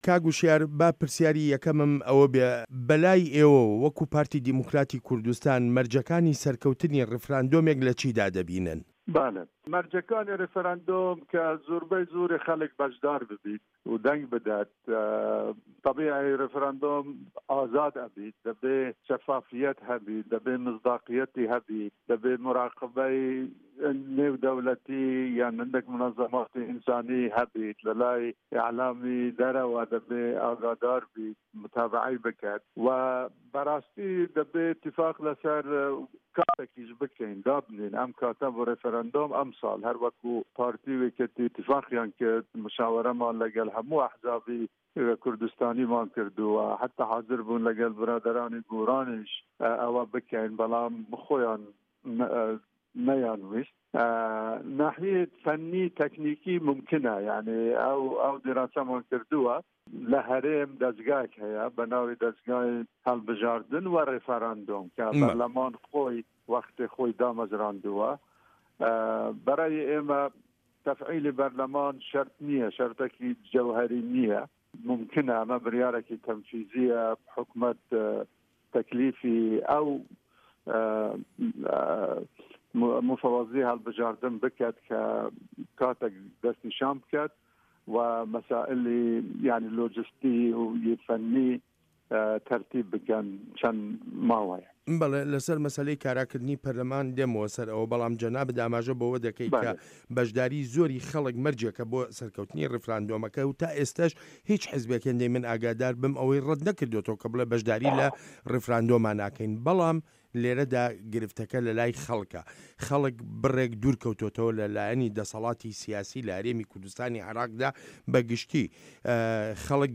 وتووێژ لەگەڵ هوشیار زێباری